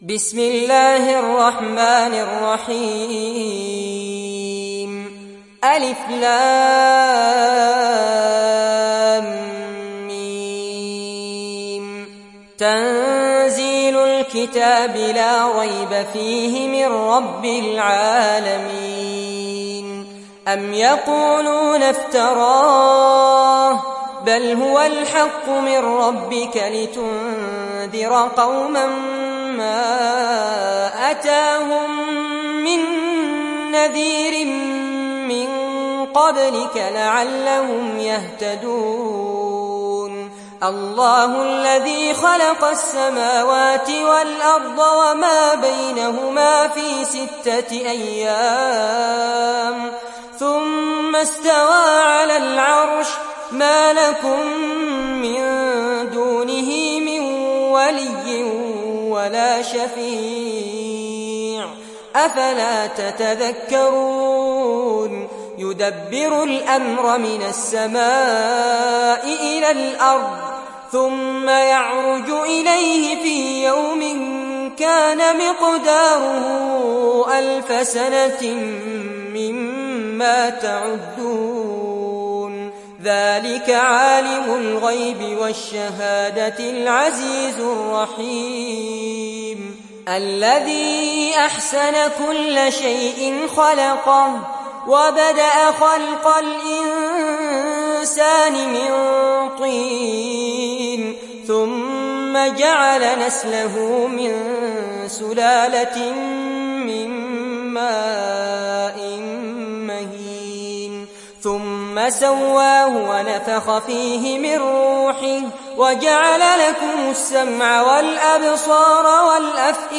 تحميل سورة السجدة mp3 بصوت فارس عباد برواية حفص عن عاصم, تحميل استماع القرآن الكريم على الجوال mp3 كاملا بروابط مباشرة وسريعة